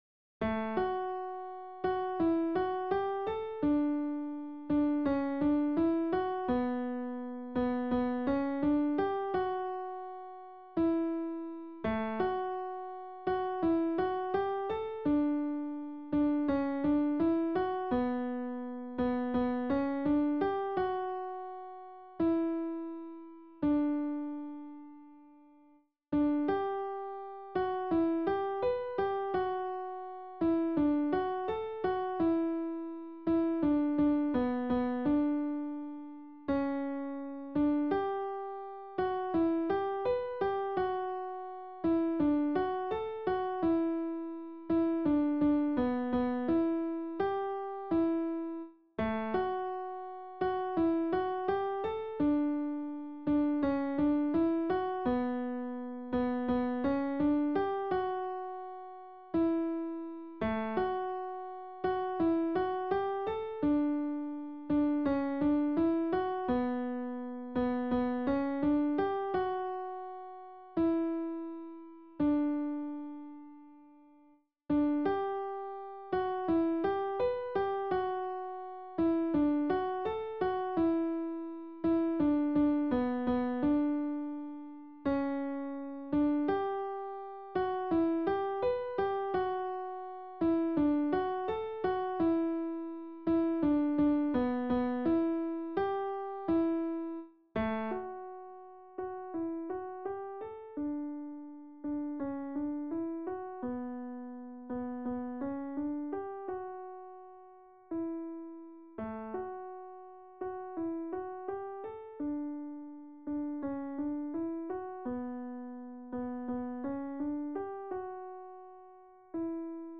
Noël fait danser les couleurs Alto.